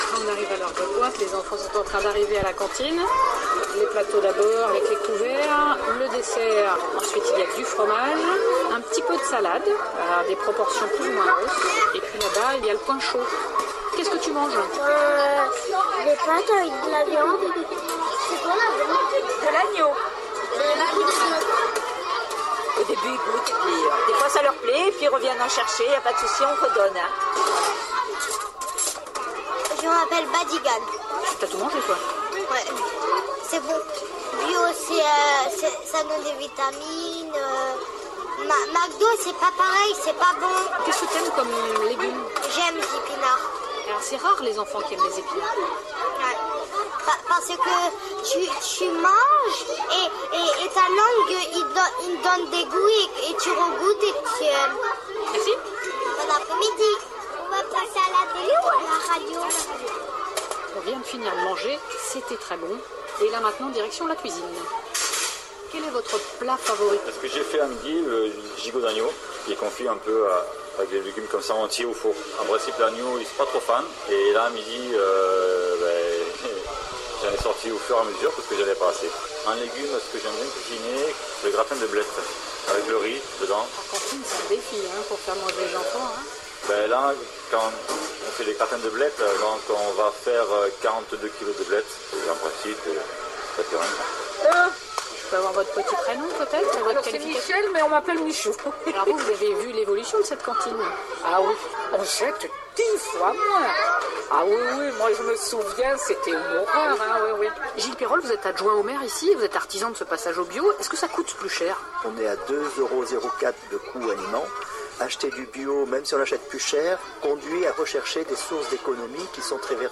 Voici un petit reportage enregistré dans le sud-est de la France et entendu un matin à la radio en décembre dernier.